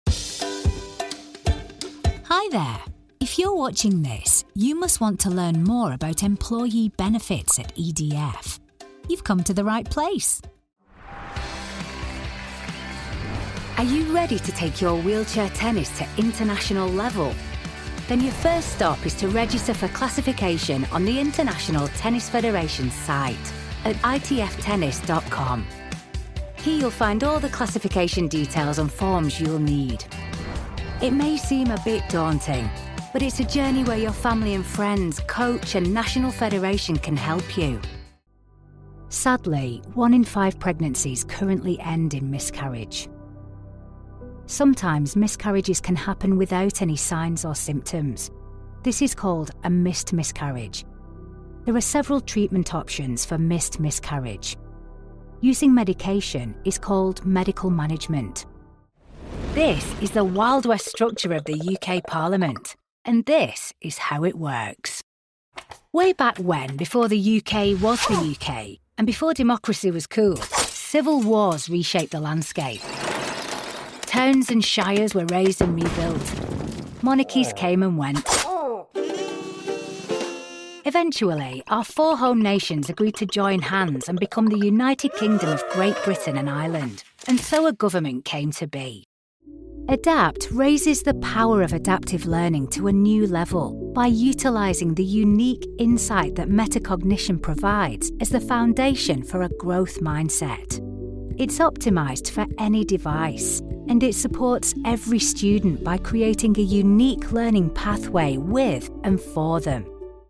Natürlich, Unverwechselbar, Vielseitig, Freundlich, Warm
Erklärvideo
A naturally northern voice that CONNECTS with warmth, depth and authenticity.